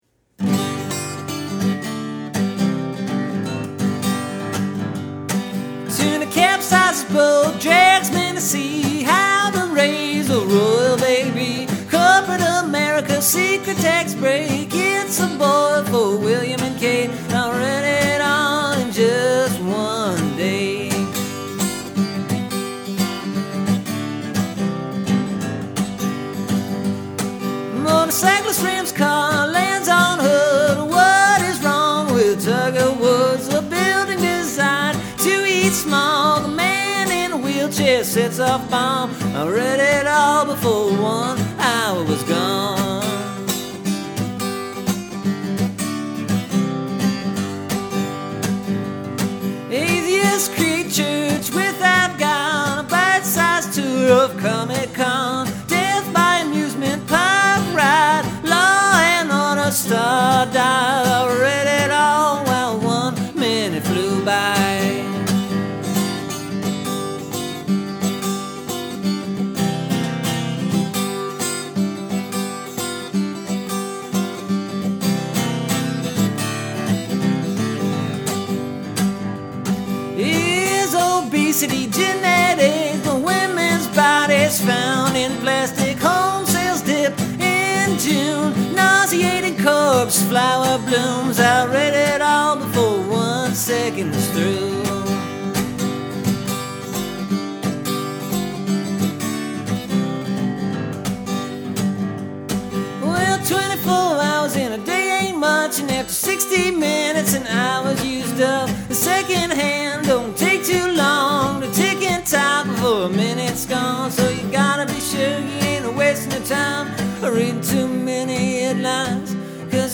Well, I tried a new melody for this version.
It’s borderline talkin’ blues, I’d say.